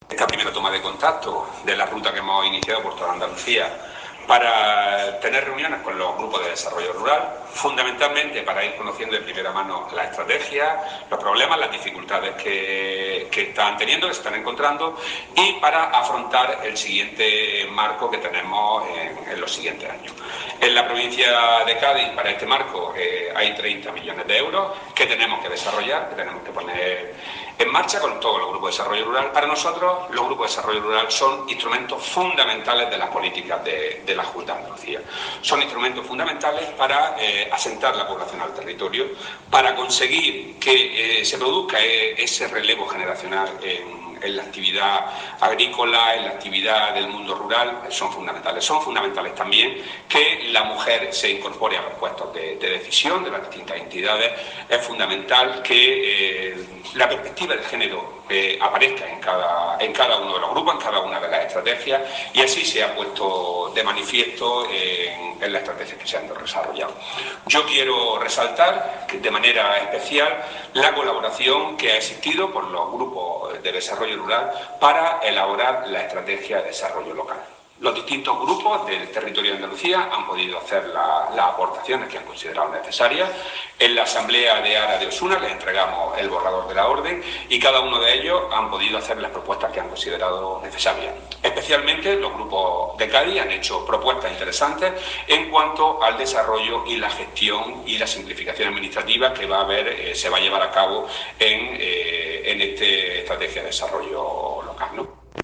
Declaraciones del consejero